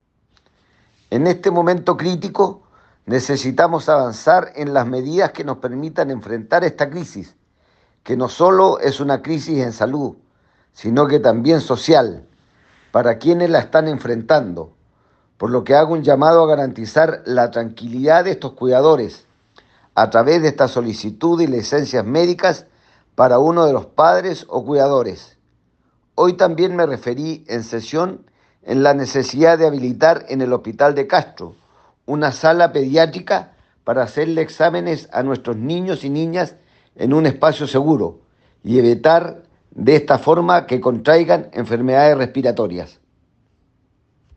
En sesión de la Cámara de Diputados y Diputadas, el parlamentario por el distrito 26, Fernando Bórquez, solicitó legislar para otorgar licencias médicas a madres, padres o cuidadoras de menores de edad que se encuentran enfrentando una situación compleja debido al virus sincicial.
En su intervención Bórquez señaló: